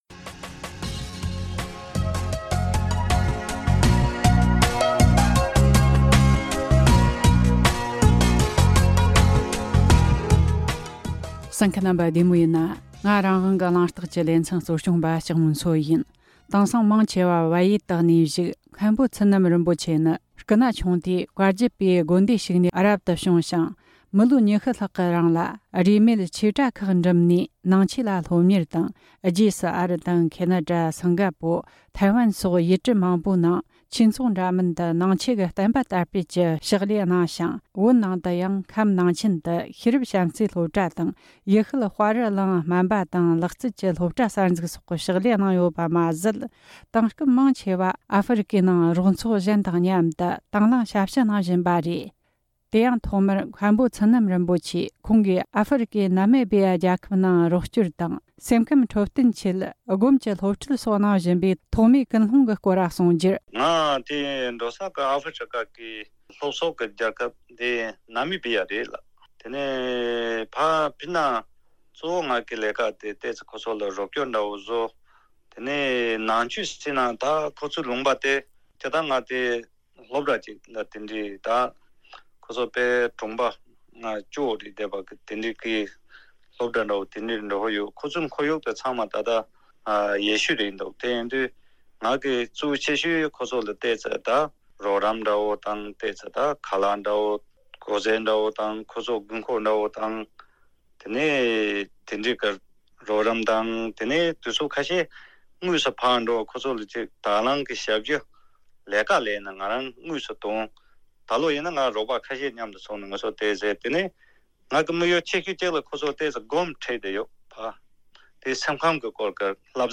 གླེང་མོལ་བྱས་བར་གསན་རོགས་གནོངས།